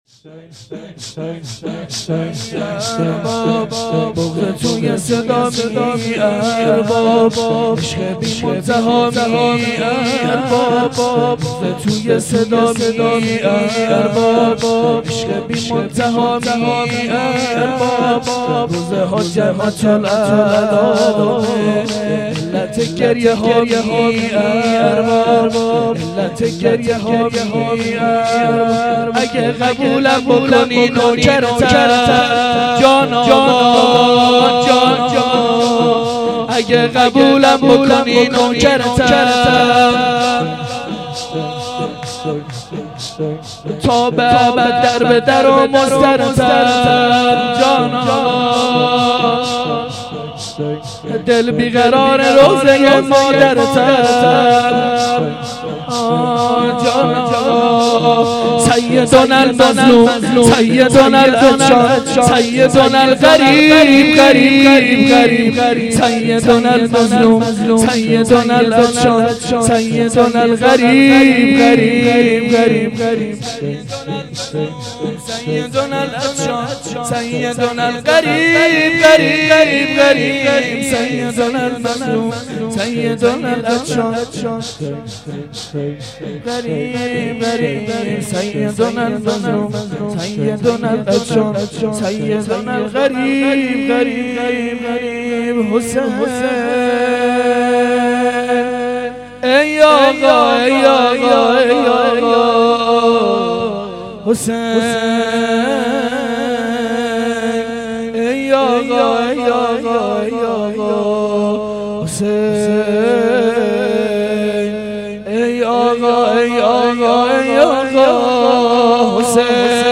شور شب دوم محرم 97